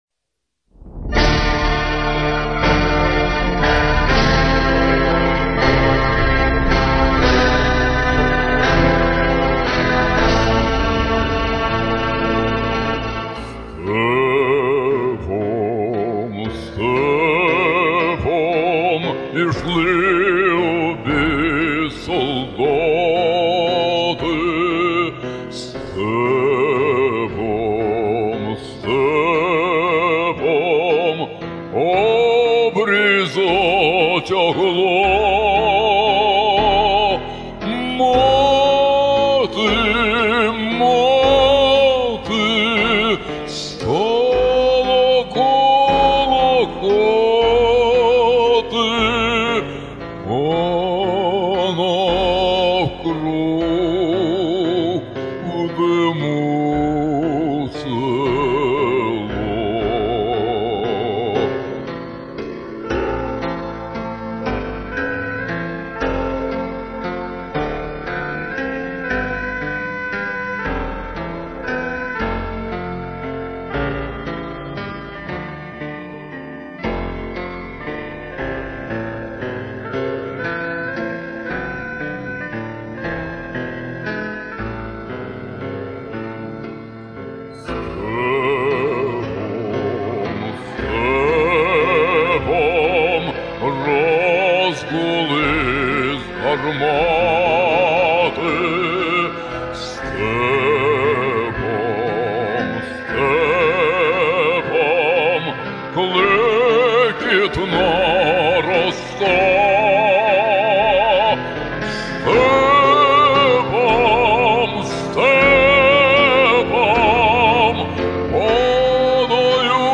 Он делает это по всем канонам классики.